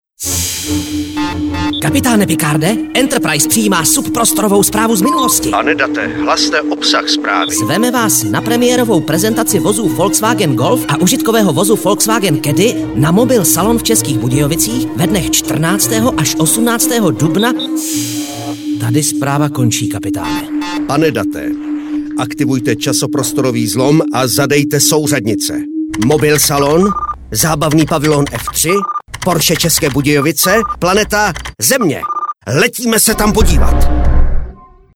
Od půlky dubna 2004 můžete na rádiu Kiss Jižní Čechy (87,8 - 97,7 - 99,3) slyšet reklamu na autosalon Porsche ve stylu Nové generace.